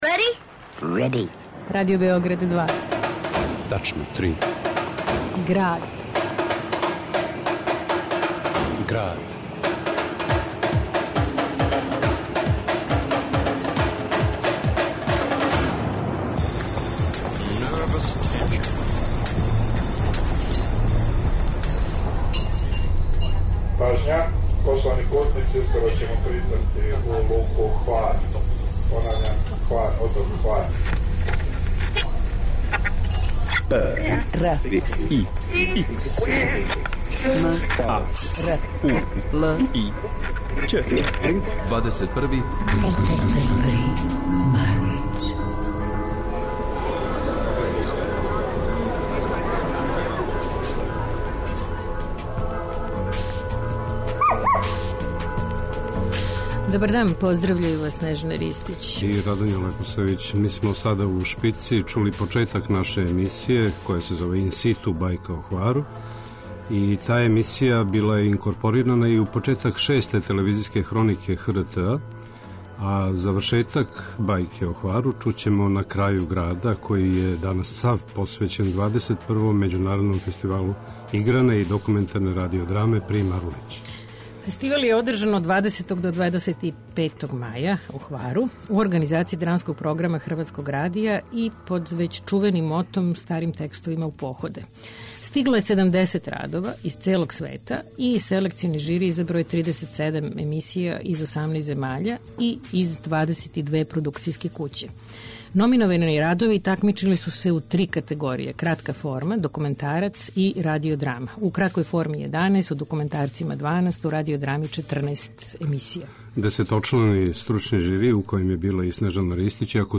документарна прича са фестивала, инсерти из награђених емисија